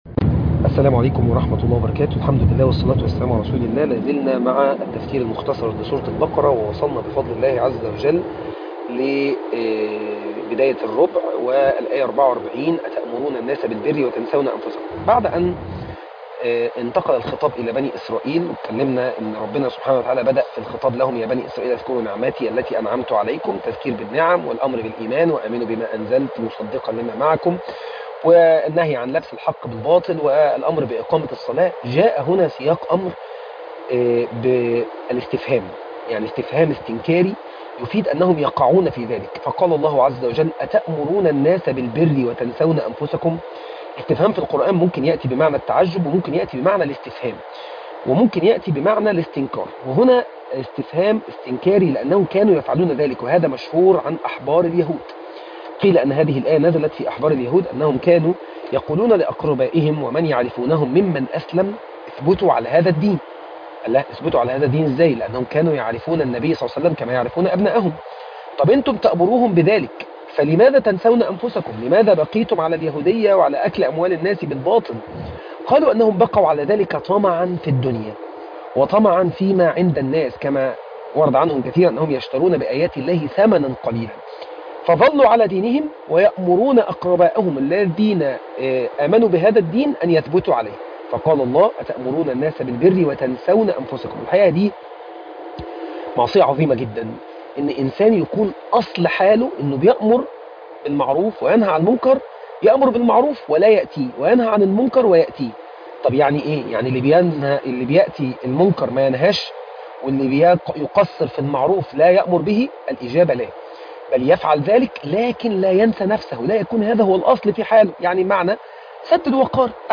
سورة البقرة 11 الآيات من44 إلي 48 التلاوة